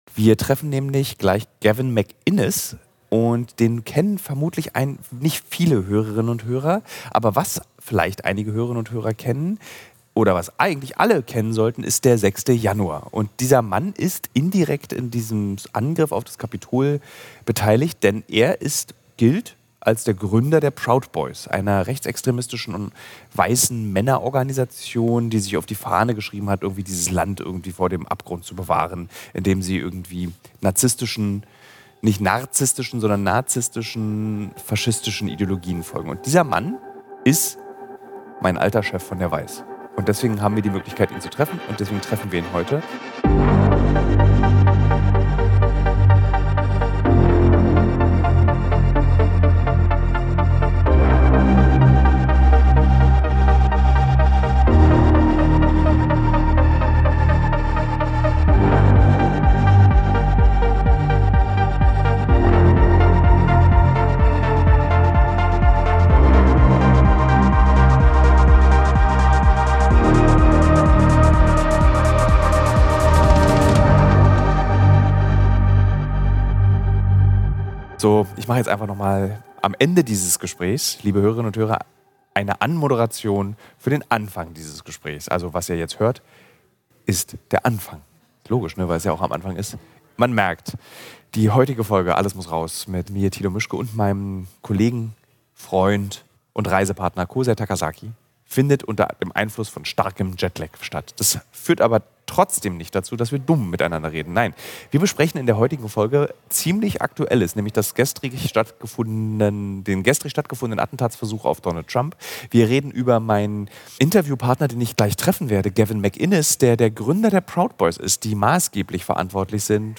Sie sprechen über die Spaltung des Landes und das, was nach der Wahl passieren könnte. Mit Jetlag sitzen die beiden im schönsten Frühstücksraum, den sie je in den USA hatten. Sie sprechen über den gestrigen Anschlagsversuch auf Donald Trump, bei dem ein Scharfschütze sich in seinem Golfclub in Florida bewaffnet in einem Gebüsch versteckt hatte.